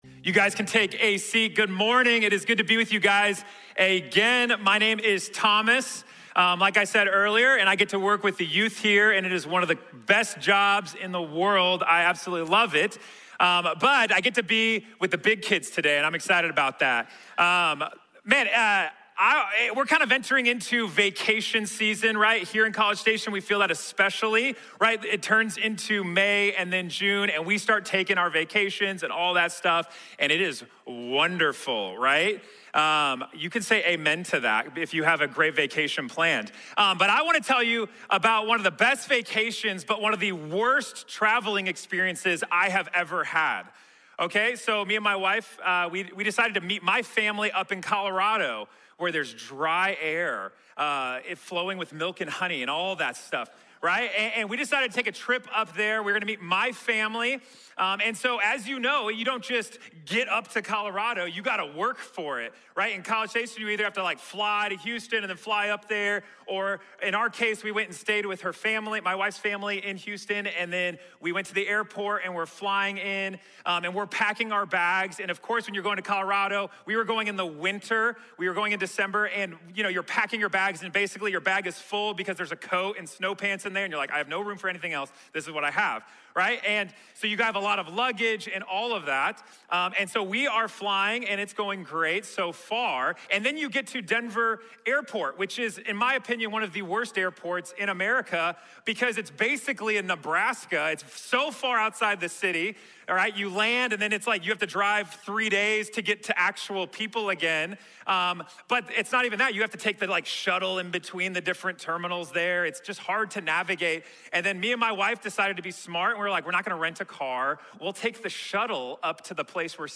Joseph | Sermón | Iglesia Bíblica de la Gracia